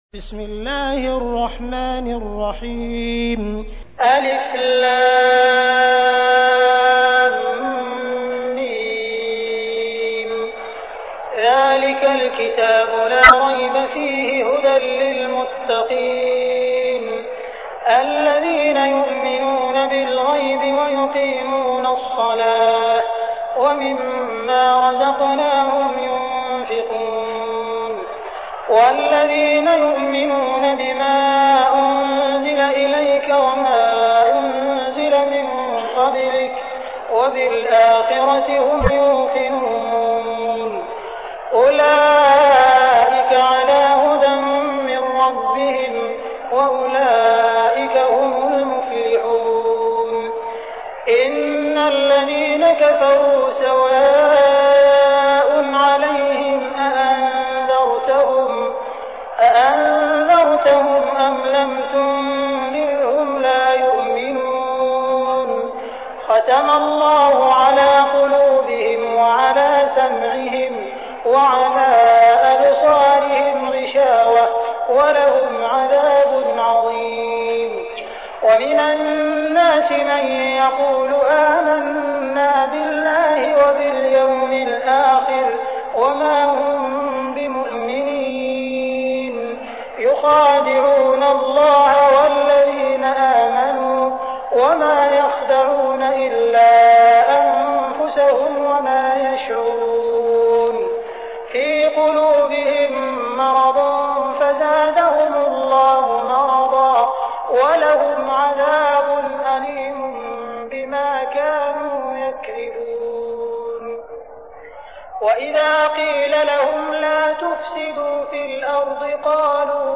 تحميل : 2. سورة البقرة / القارئ عبد الرحمن السديس / القرآن الكريم / موقع يا حسين